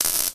electric_hit.ogg